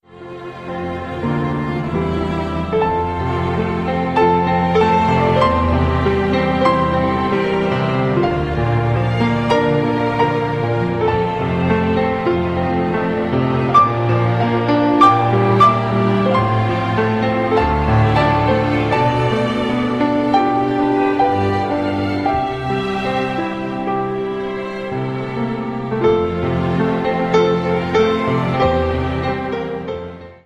Hymns with Classical and Original Themes
Collagen und eindringlichen Lobpreis im Caféhaus Stil.
• Sachgebiet: Praise & Worship